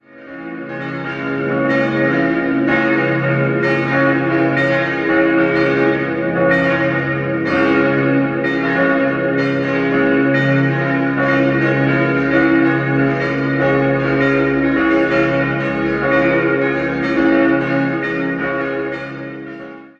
Die Reformierte Martinskirche wurde 1515 erbaut, der Turm ist allerdings schon wesentlich älter. 5-stimmiges Geläut: des'-f'-as'-b'-c'' Die Glocke wurden 1964 von der Gießerei Rüetschi in Aarau gegossen.